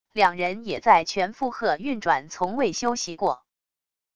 两人也在全负荷运转从未休息过wav音频生成系统WAV Audio Player